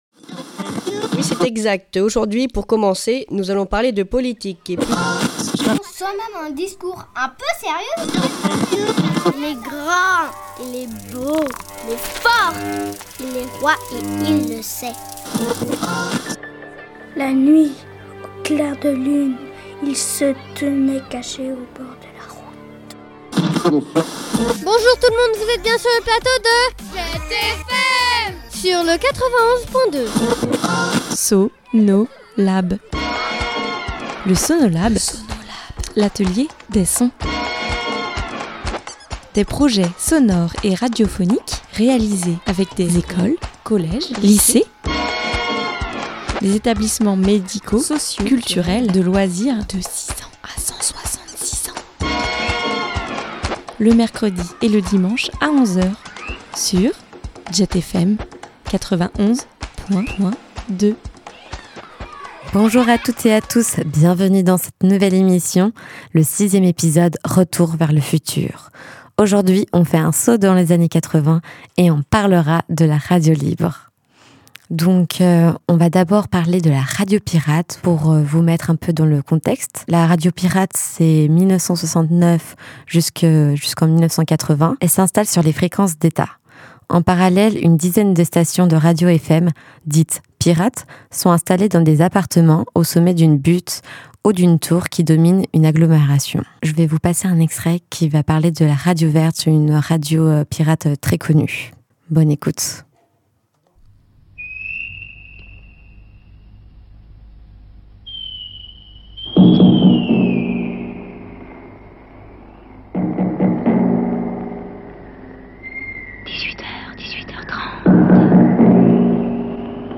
Aujourd’hui on fait un saut dans les années 80, et on parlera de la RADIO LIBRE. Je me suis servie des archives de l’INA pour résumer cette histoire et la vivre sensoriellement.